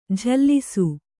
♪ jhallisu